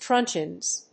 /ˈtrʌntʃɪnz(米国英語)/